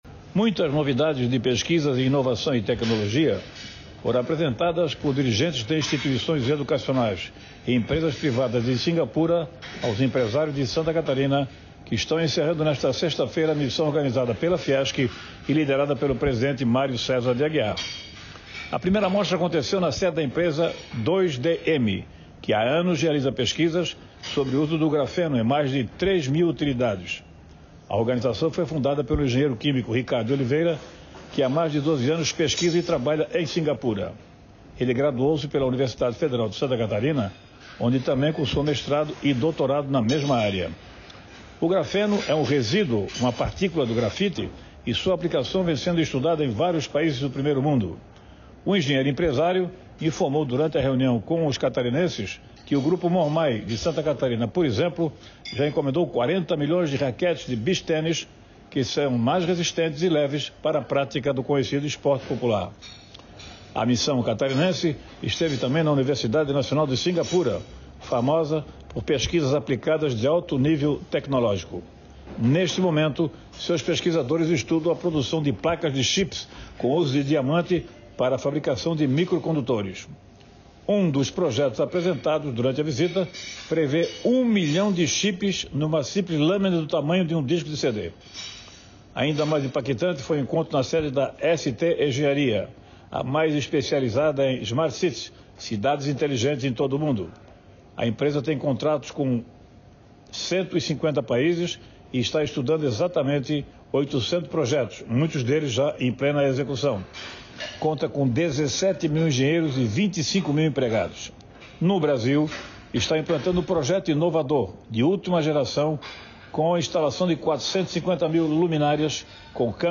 Jornalista comenta mais detalhes das empresas e entidades visitadas pela comitiva empresarial catarinense em Singapura.